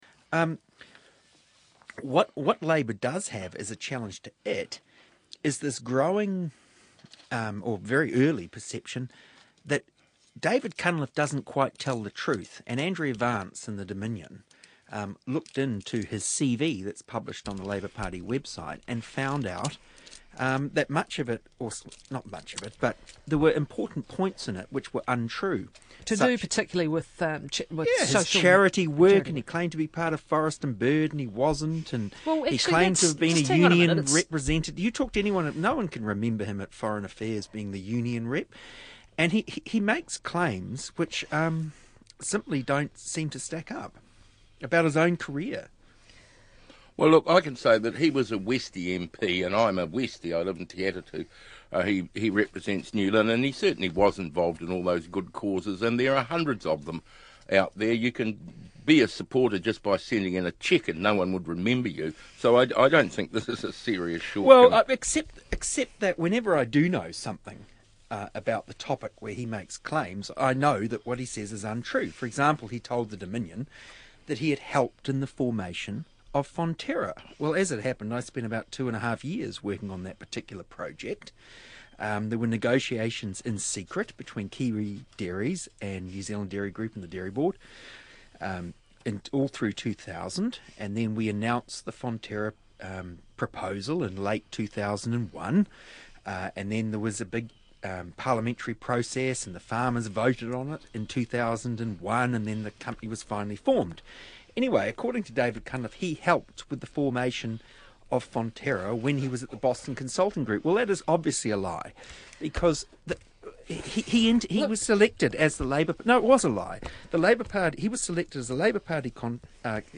What a cynical, nasty performance.